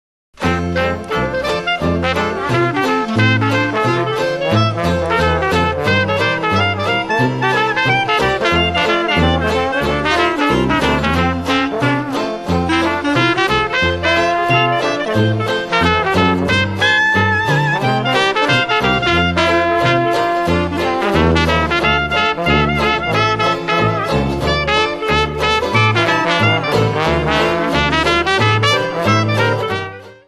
Jazz, Swing Bands
Dixieland jazz band plays happy foot-tapping, Trad Jazz.